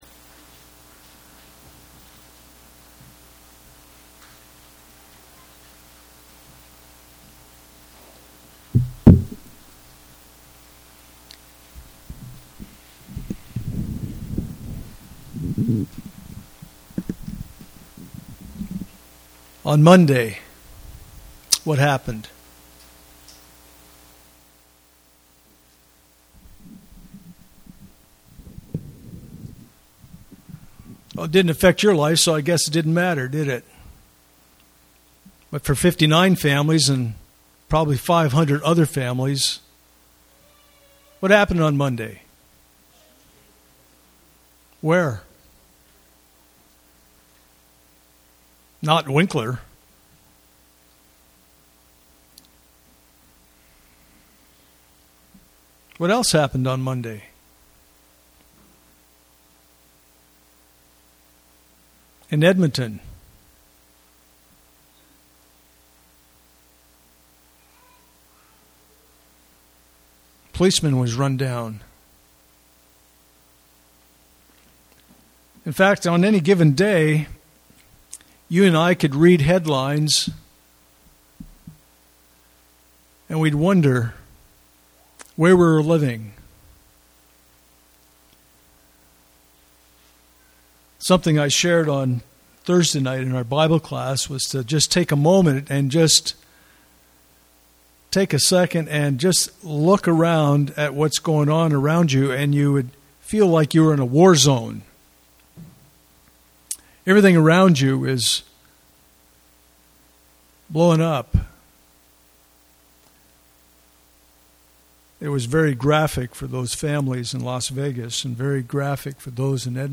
Ephesians 5:1-7 Service Type: Sunday Morning « Are You a Paul?